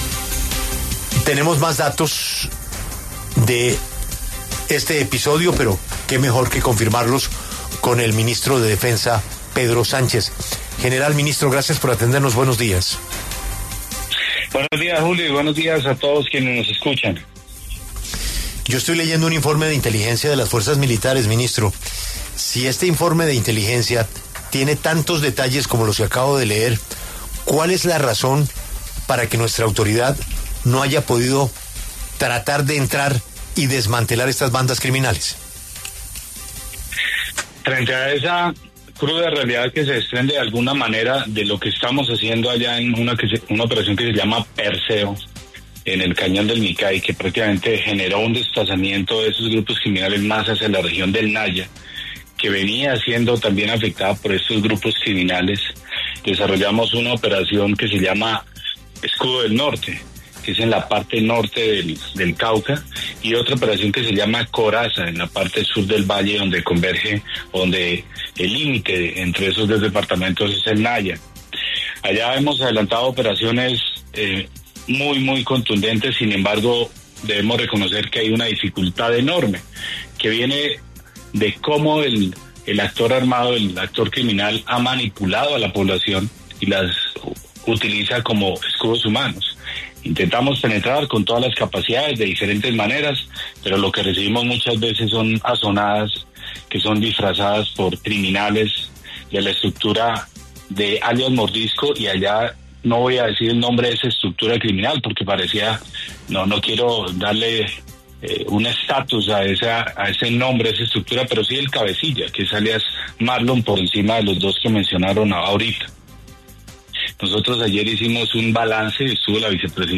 El ministro de Defensa, Pedro Sánchez, habla en La W sobre la situación de orden público que se vive en el Cauca tras las recientes asonadas contra el Ejército.